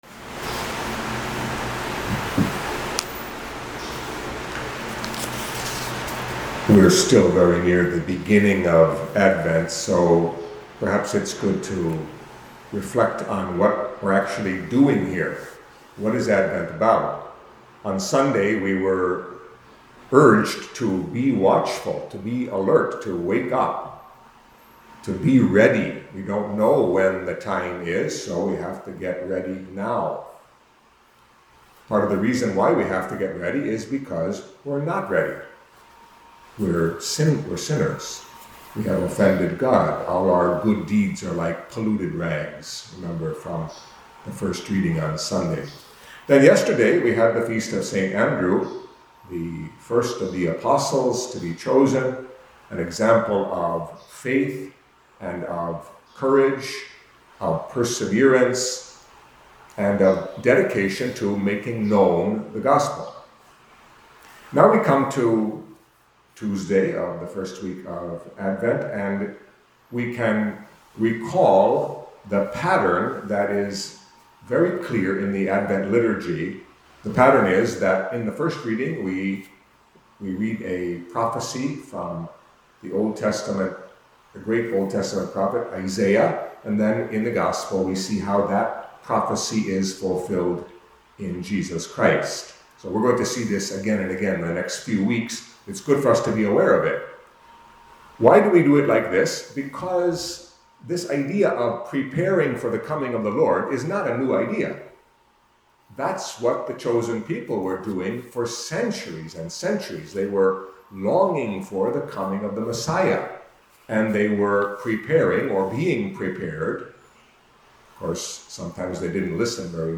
Catholic Mass homily for Tuesday of the First Week of Advent